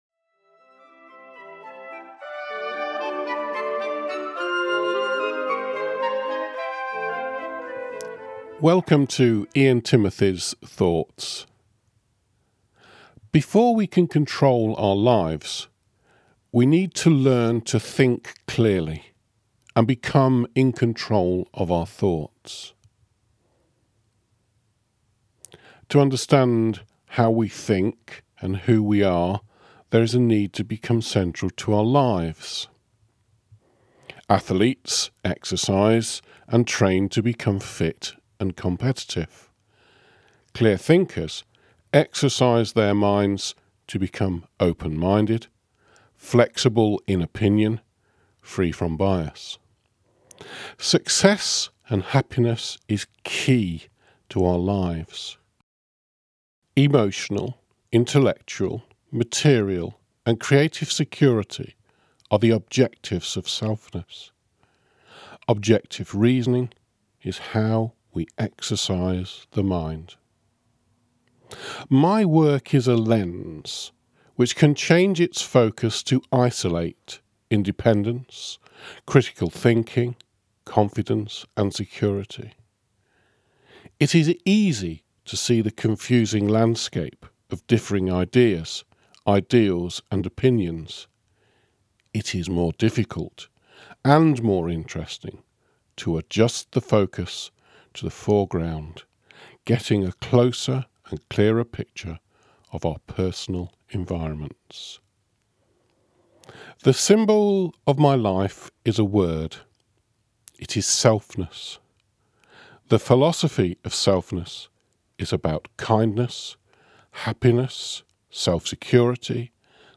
The interview lasts for approximately sixteen minutes.